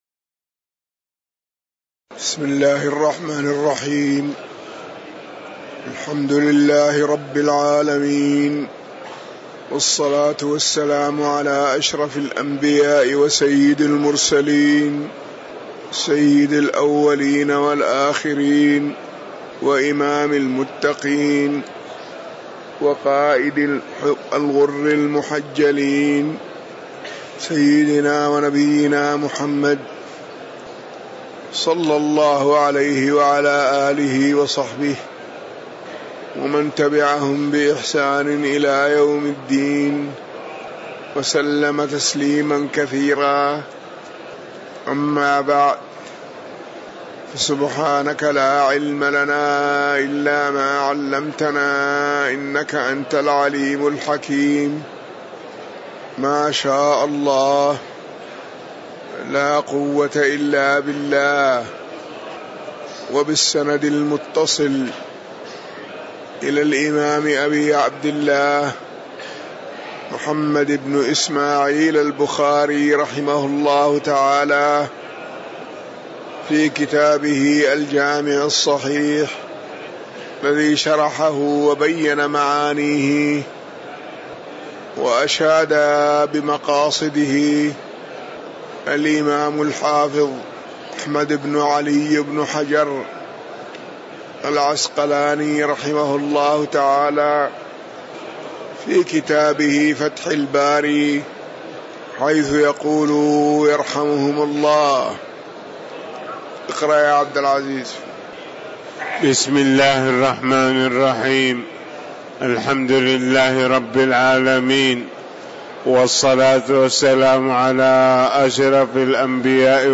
تاريخ النشر ٤ محرم ١٤٤١ هـ المكان: المسجد النبوي الشيخ